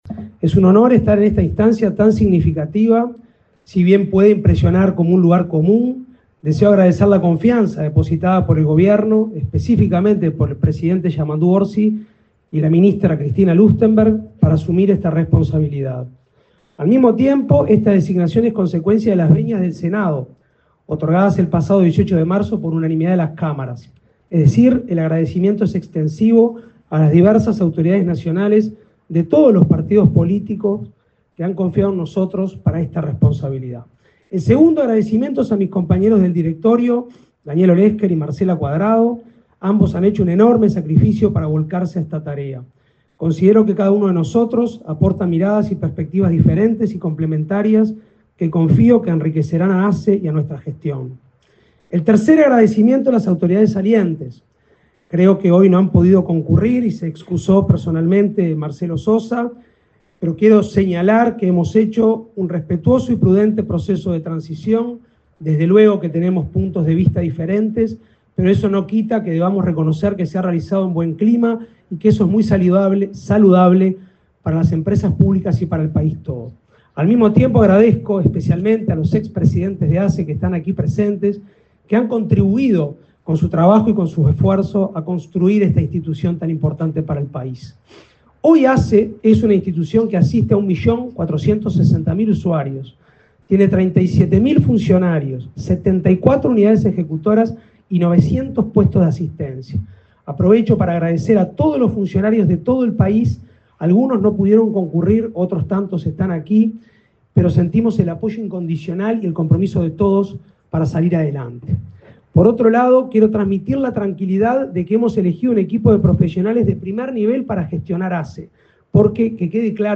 Palabras de autoridades en asunción en ASSE
Palabras de autoridades en asunción en ASSE 02/04/2025 Compartir Facebook X Copiar enlace WhatsApp LinkedIn Este miércoles 2, asumieron en la Administración de los Servicios de Salud del Estado (ASSE), su presidente, Álvaro Danza; su vicepresidente, Daniel Olesker, y, como vocal, Marcela Cuadrado. Durante la ceremonia, además de Danza, se expresó la ministra de Salud Pública, Cristina Lustemberg.